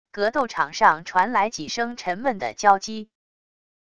格斗场上传来几声沉闷的交击wav音频